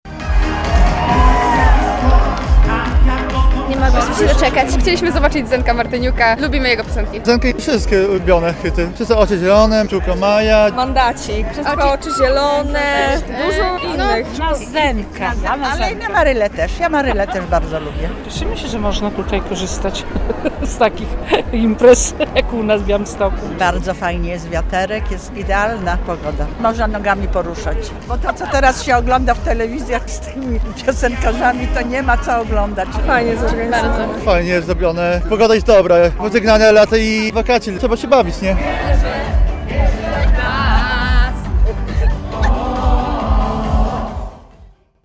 Tłumy przyszły na białostocki Rynek Kościuszki w pierwszy wieczór dwudniowej imprezy "Białystok pełen muzyki".
relacja